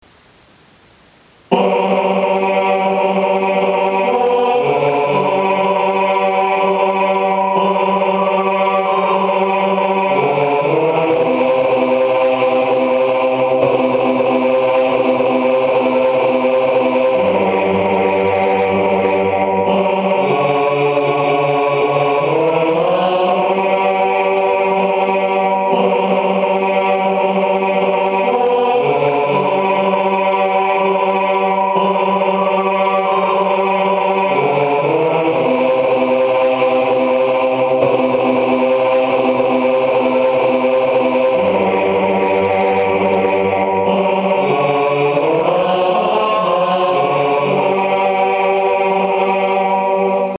Hierna  de klankband van de alt- en basstem van het lied
160916_tenor+bas.MP3 (394.7 KB)